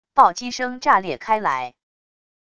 暴击声炸裂开来wav音频